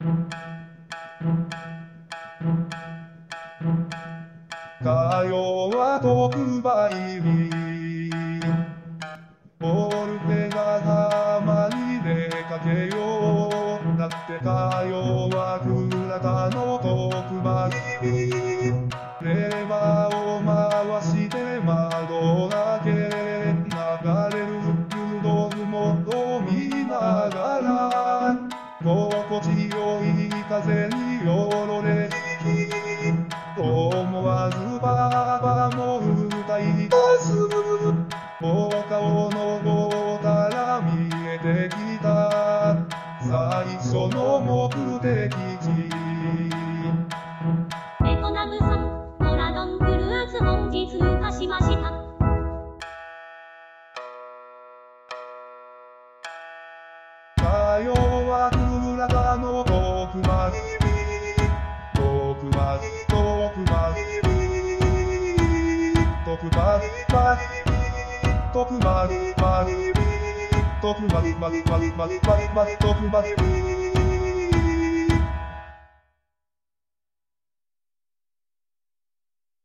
盆踊り